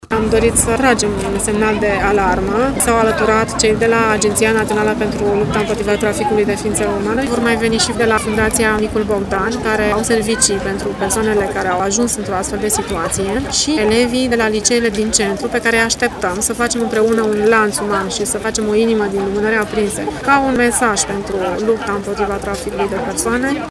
Evenimentul de conștietizare al persoanelor a avut loc în Piața Sfatului.